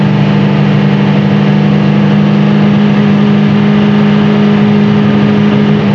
rr3-assets/files/.depot/audio/Vehicles/v8_nascar/nascar_low_3000.wav
nascar_low_3000.wav